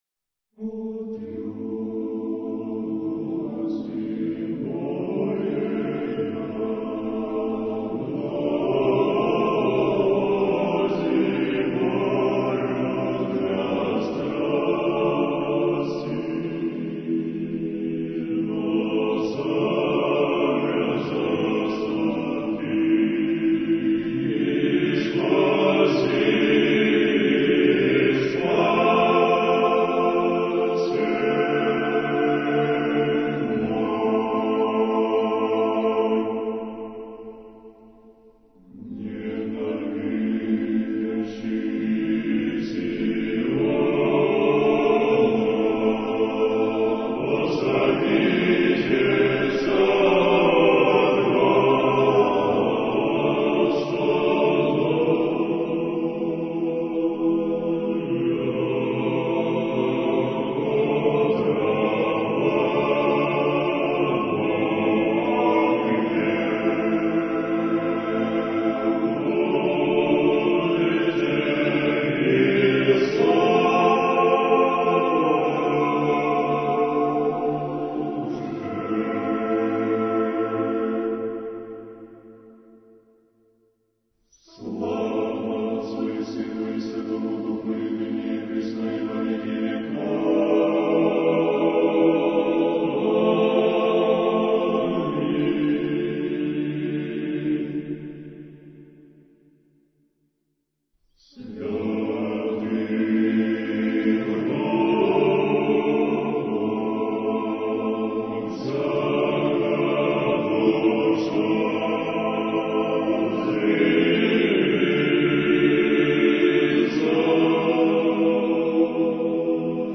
Архив mp3 / Духовная музыка / Русская /